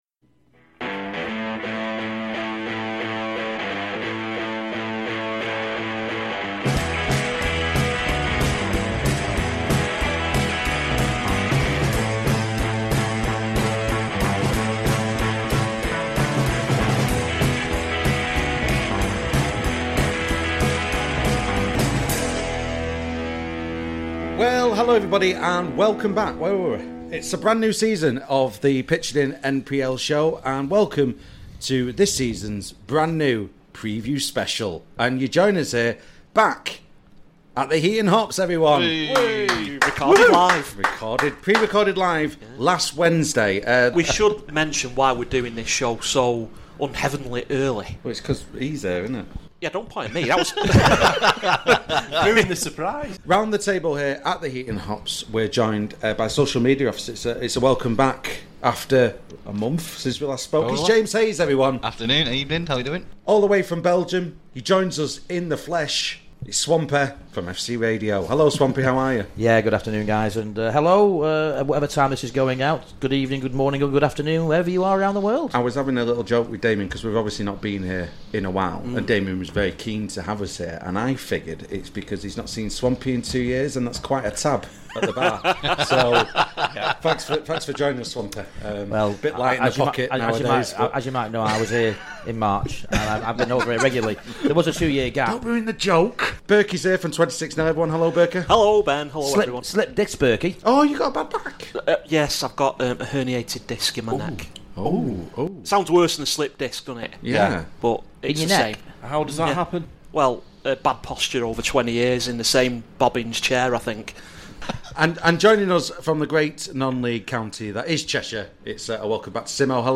This programme was recorded at a virtual Heaton Hops on Wednesday 27th July 2022.